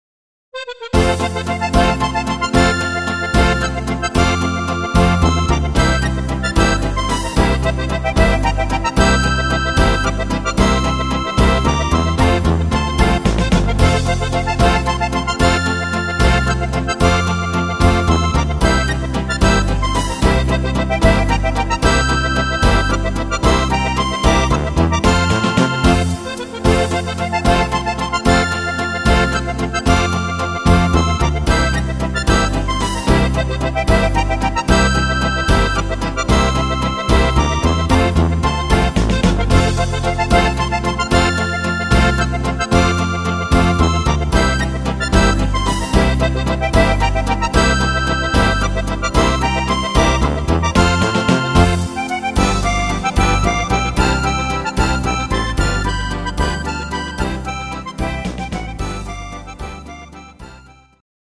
Steirische Harmonika